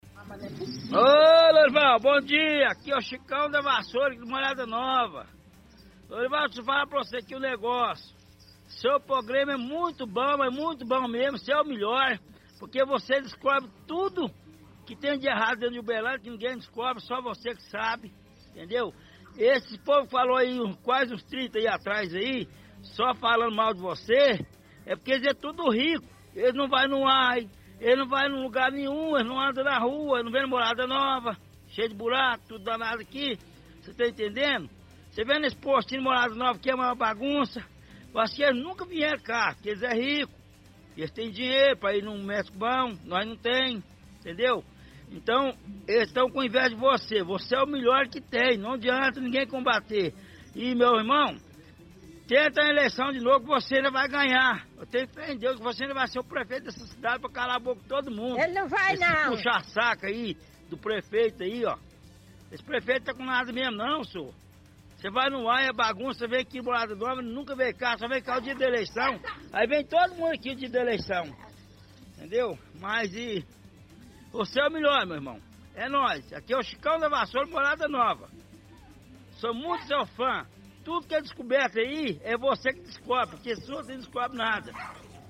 – Ouvinte fala que o programa é muito bom porque deixa transparente tudo de ruim que está acontecendo em Uberlândia.